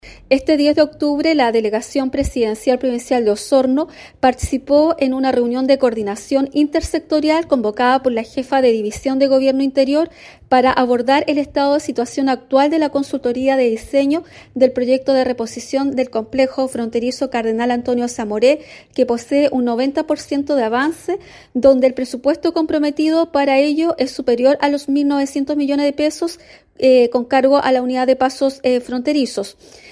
La delegada presidencial provincial de Osorno, Claudia Pailalef Montiel, explicó que se espera terminar la consultoría de diseño que contempla nueva infraestructura del complejo fronterizo.